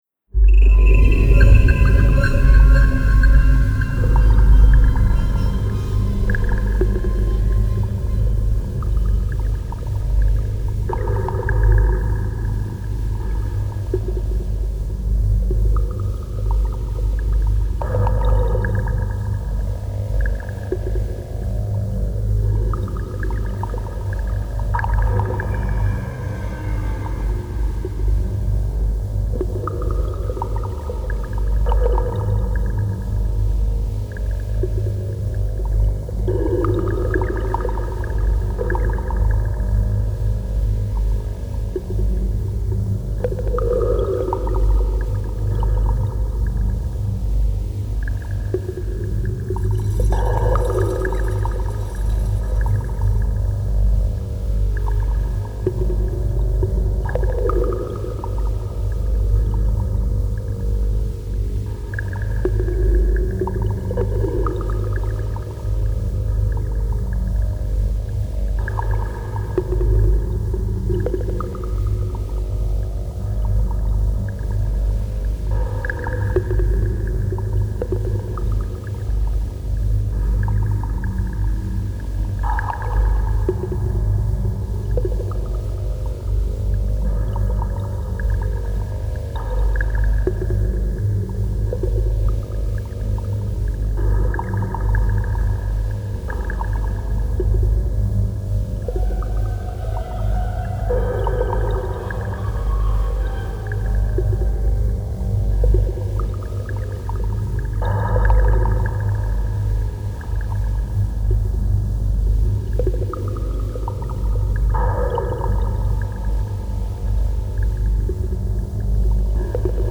徐々に聞こえてくるリズムも怪しく、オープニングやエンディングなどにも合うBGM素材です。
テンポ：♩=free
主な使用楽器：シンセパッド etc